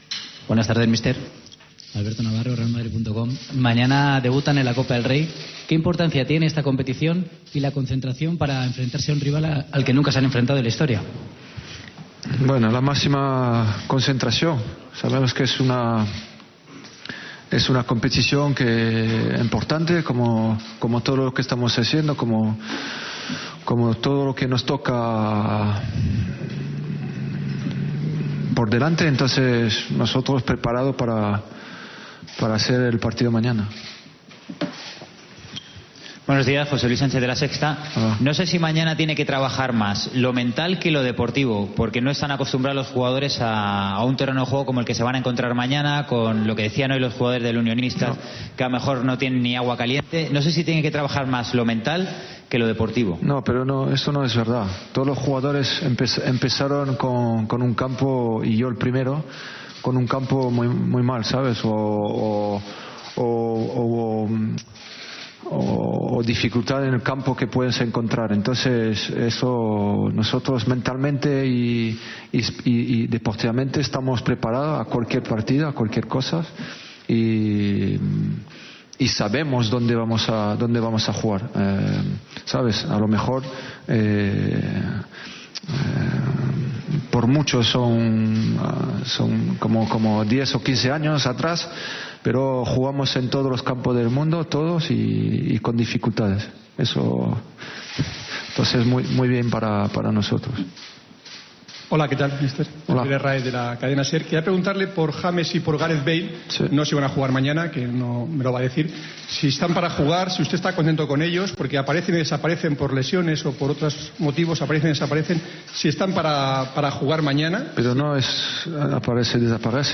El entrenador del Real Madrid, Zinedine Zidane, ha atendido a los medios en la previa del encuentro de dieciseisavos de final de la Copa del Rey ante el Unionistas en Salamanca.